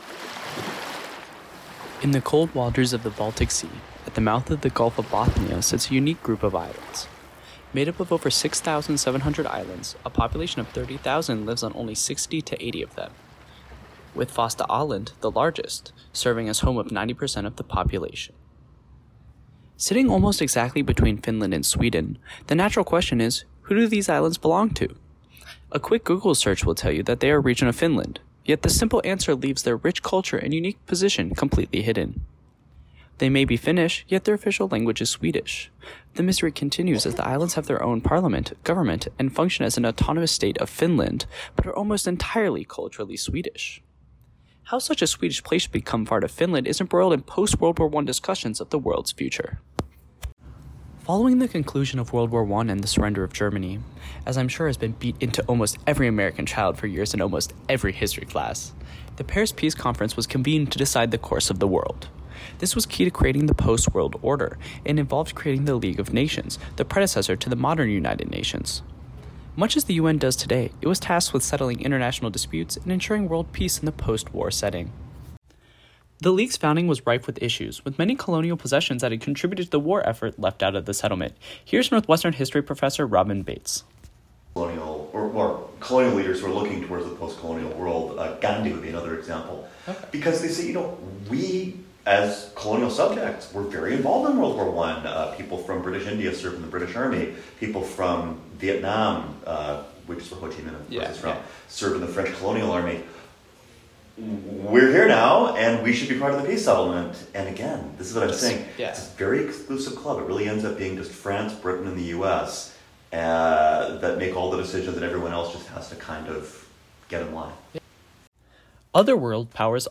This story originally aired as part of our A.I. Special Broadcast.